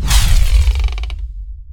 Staff_Door.ogg